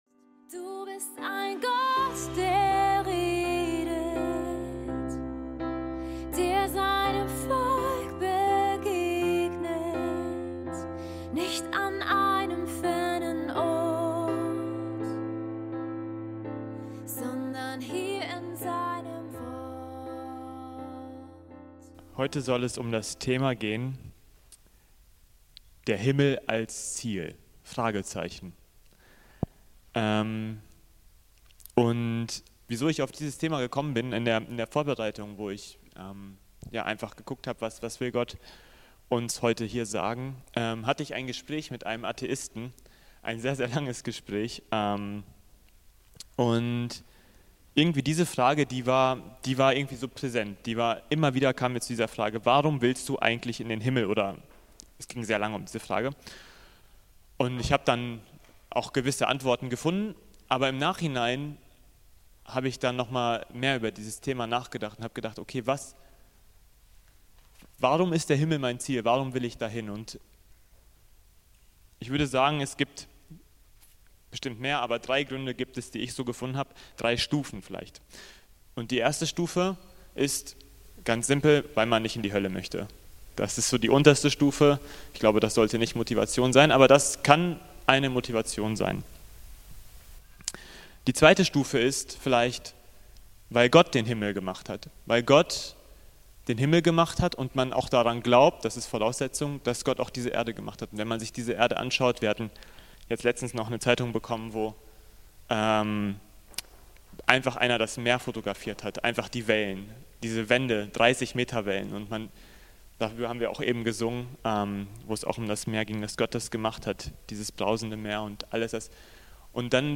Die Predigt ist aus dem Gottesdienst vom 28. April 2024.